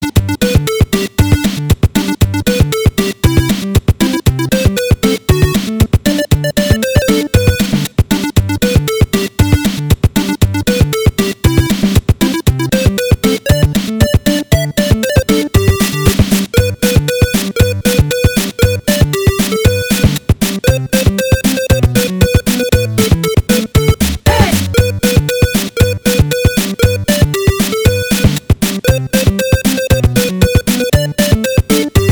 20:15, 17 August 2011 03 - Jinge Bells (8Bit Version).mp3 (